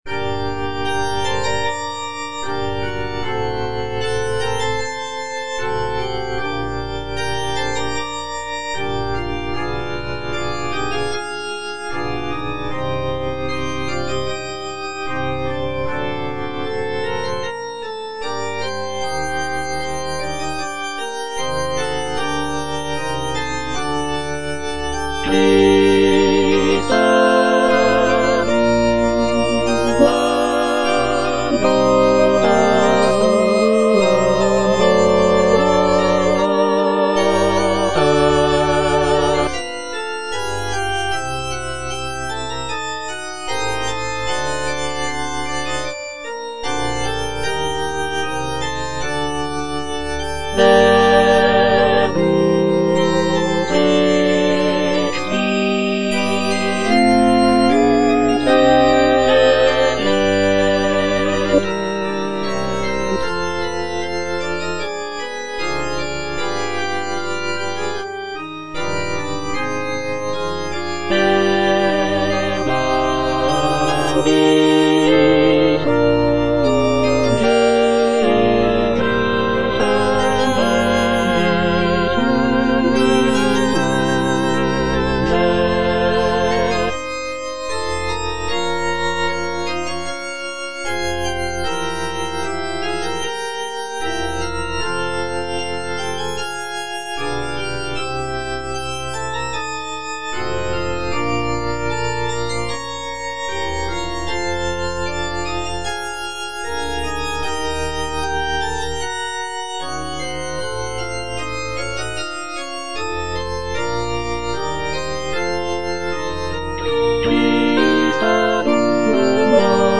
Cantata
All voices